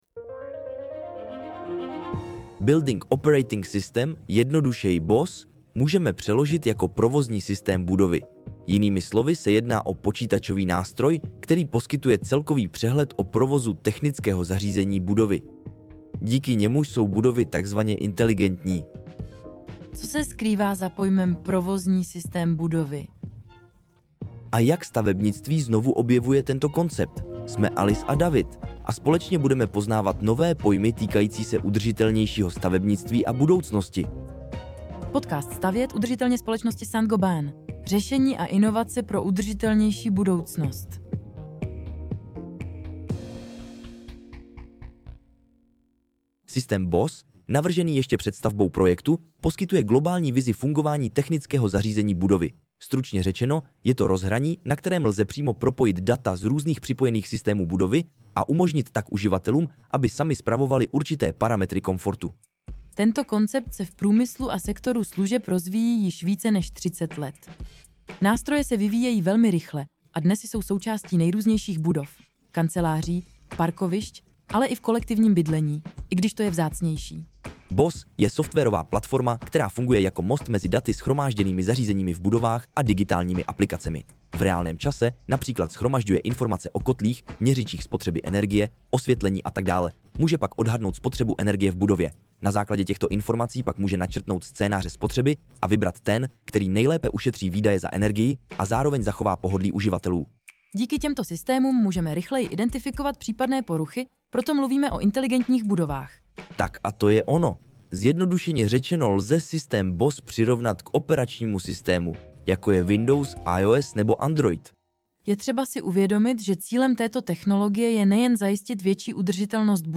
Cílem této technologie je zajistit větší udržitelnost budov, ale také optimální komfort pro uživatele. 🌱 Tento podcast pro vás z francouzského originálu přeložila a také namluvila umělá inteligence, aby i ten byl ekologicky šetrný a pomáhal nám snižovat uhlíkovou stopu.